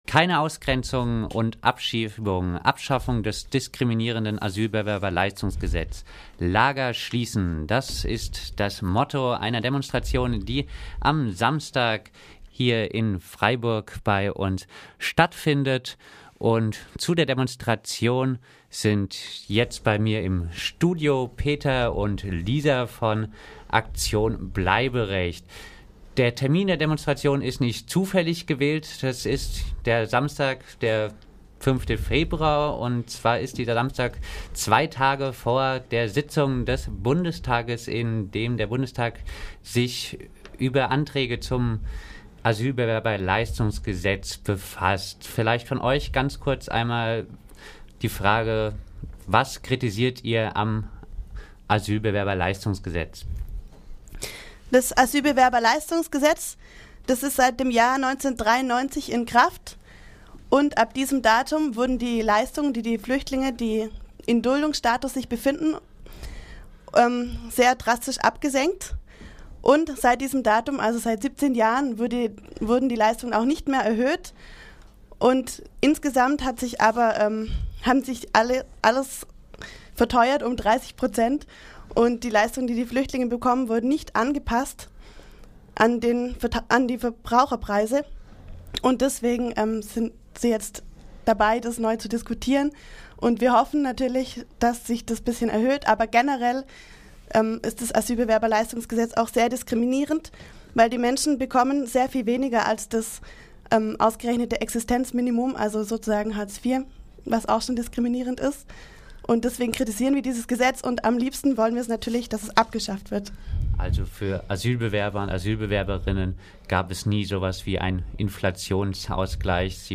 Gespräch mit zwei Aktion BleiberechtsaktivistInnen zur Demo am Samstag mit dem Titel: Keine Ausgrenzungen und Abschiebungen!